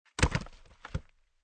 fallingonions.ogg